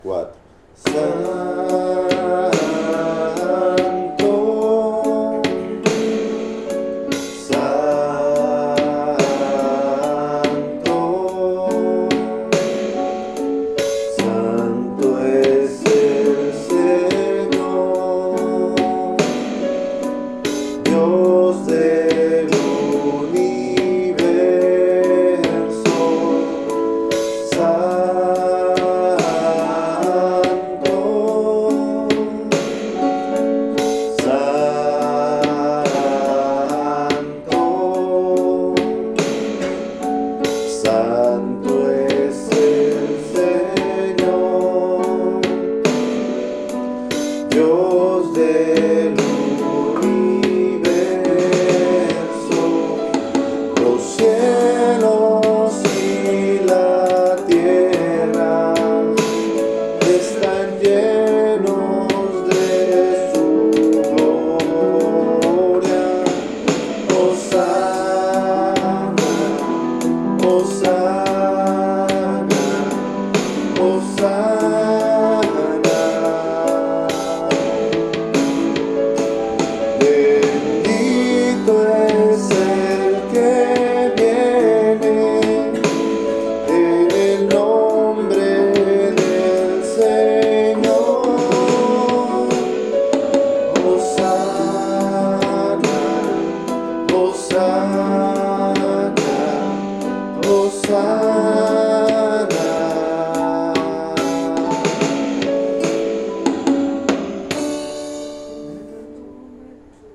Tempo: 72